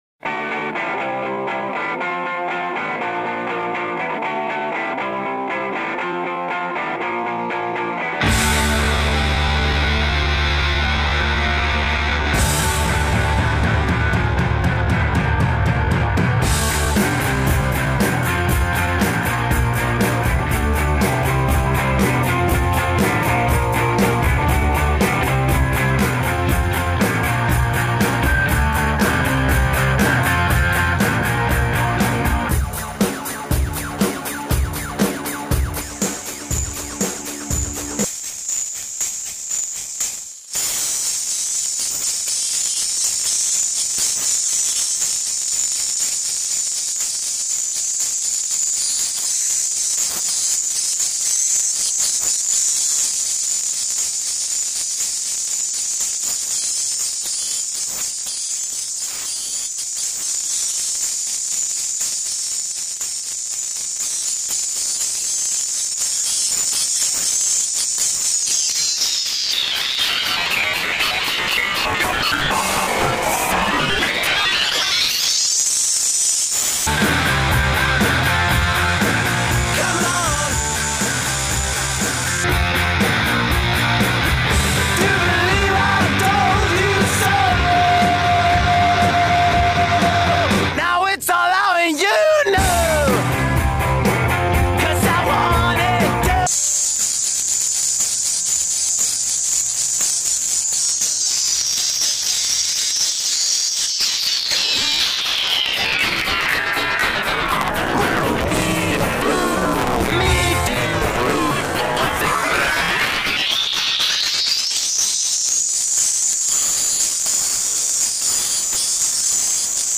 VST effect plugin